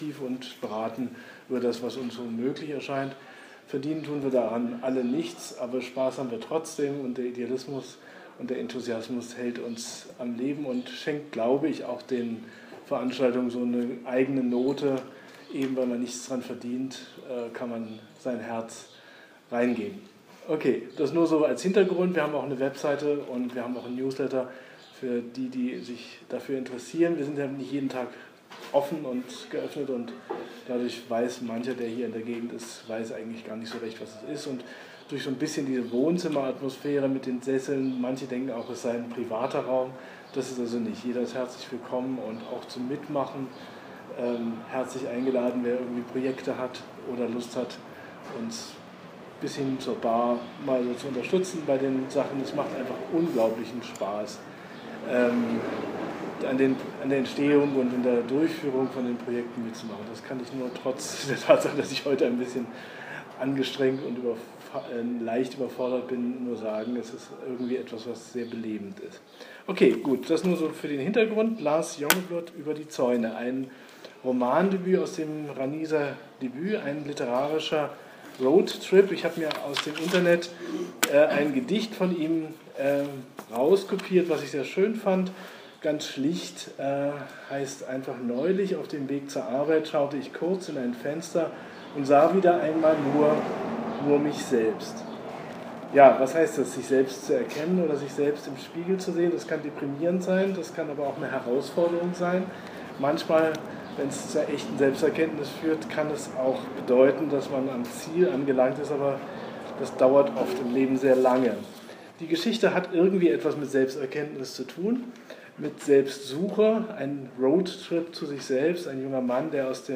Audio zur Lesung
LiteraTour Neukölln – 06. bis 10. Juni 2018